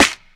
Waka SNARE ROLL PATTERN (56).wav